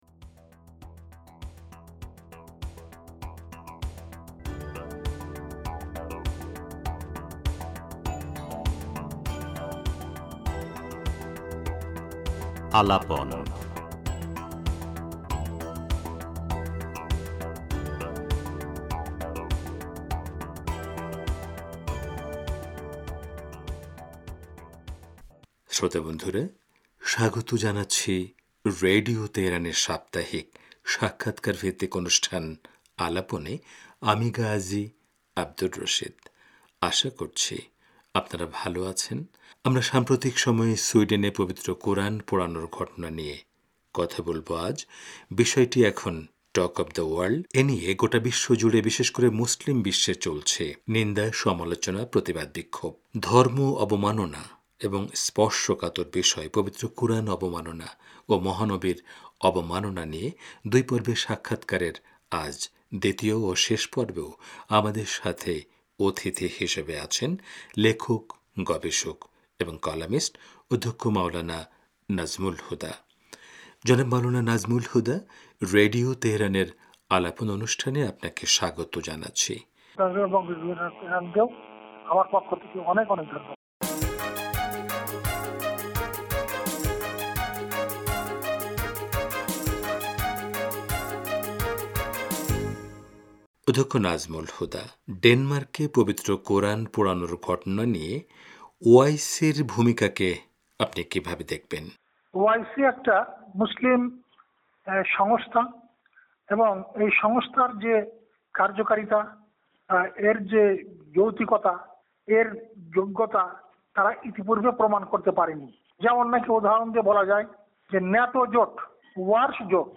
সাপ্তাহিক সাক্ষাৎকারভিত্তিক অনুষ্ঠান